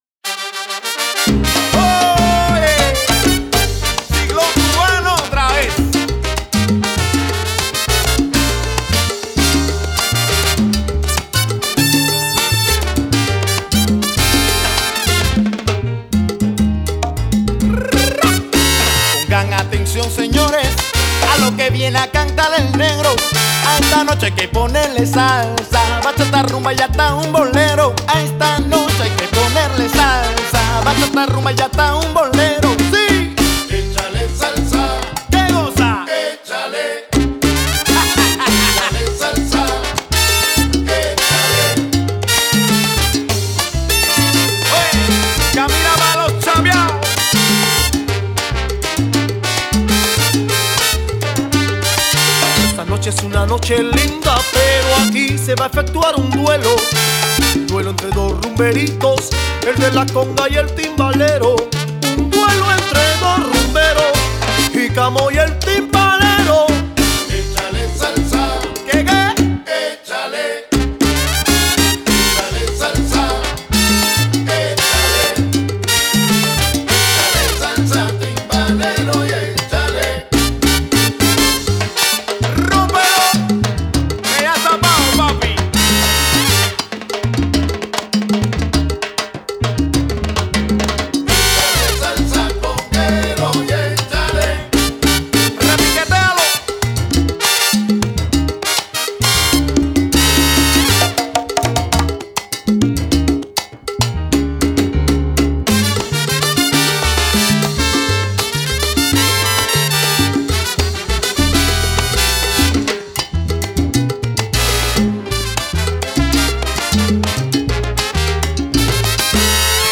• Категория:Музыка для сальсы